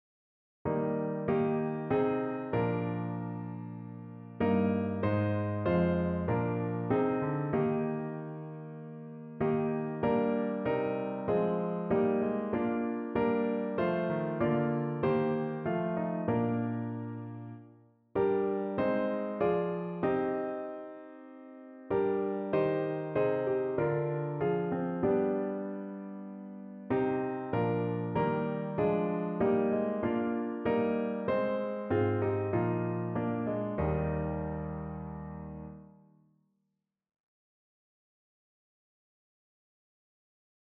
Abendlied